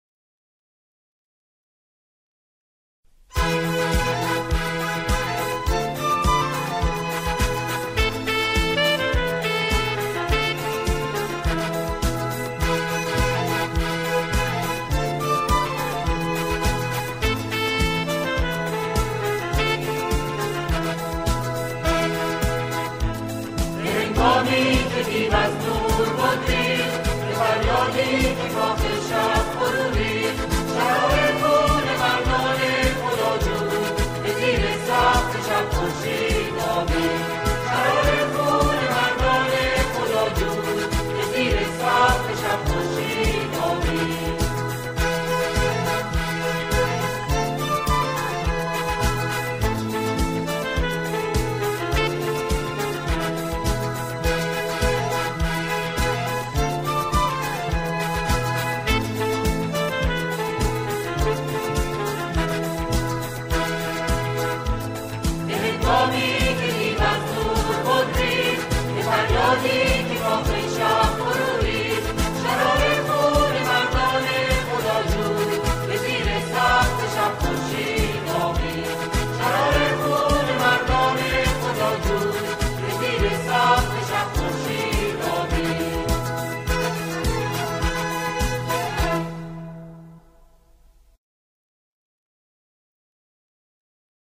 آنها در این اثر، شعری را درباره دهه فجر همخوانی می‌کنند.